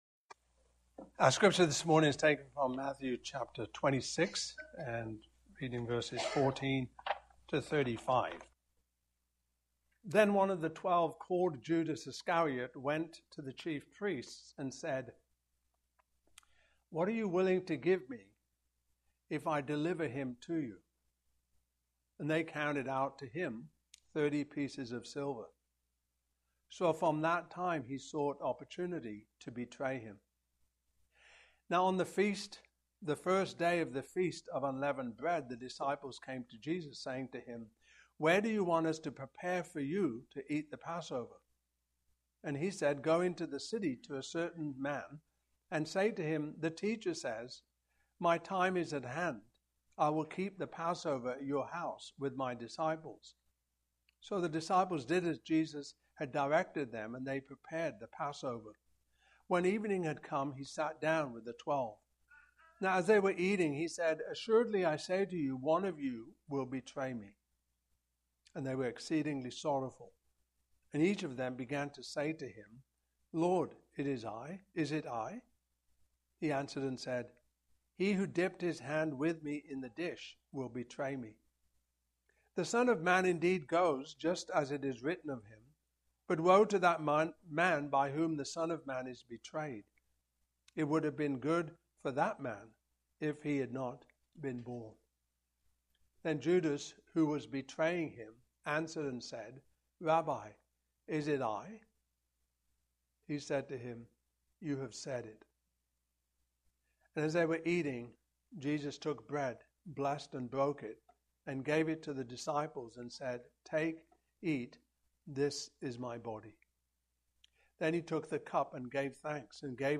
Matthew 26:14-35 Service Type: Morning Service « Saints